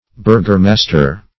Burghermaster \Burgh"er*mas`ter\, n.
burghermaster.mp3